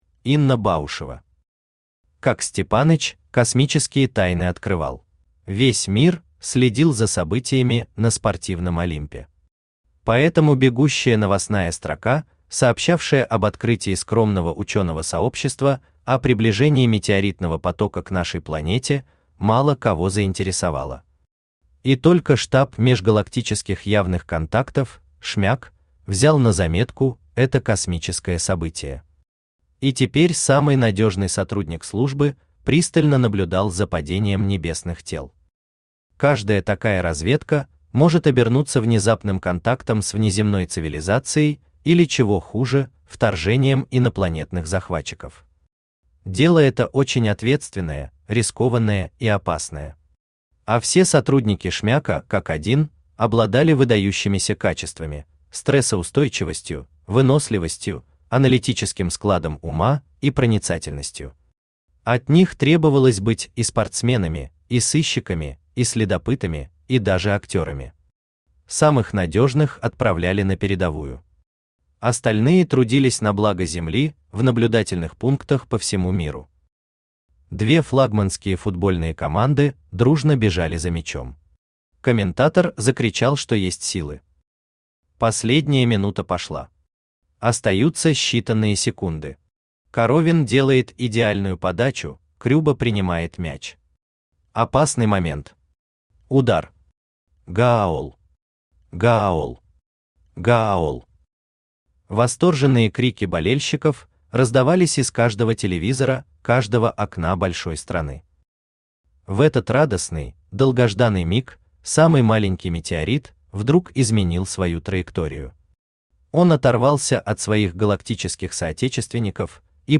Аудиокнига Как Степаныч космические тайны открывал | Библиотека аудиокниг
Aудиокнига Как Степаныч космические тайны открывал Автор Инна Баушева Читает аудиокнигу Авточтец ЛитРес.